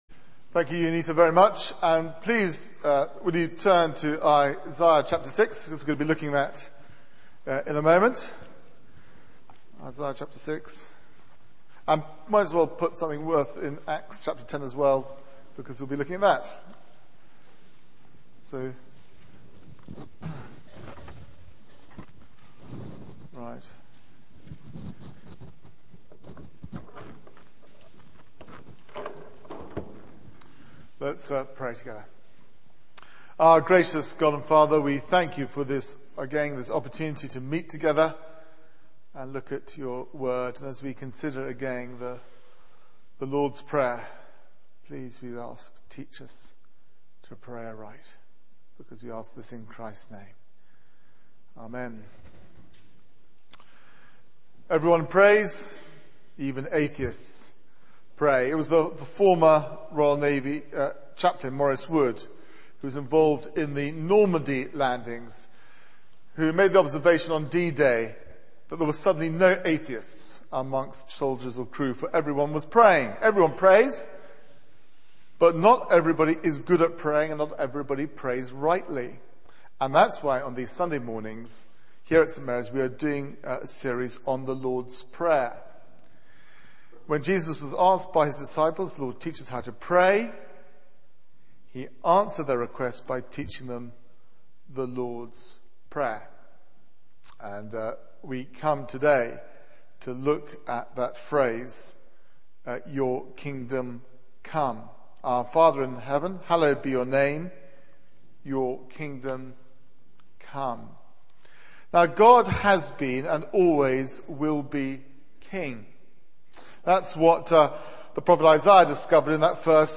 Media for 9:15am Service on Sun 19th Sep 2010 09:15 Speaker
Theme: 'Your Kingdom come' Sermon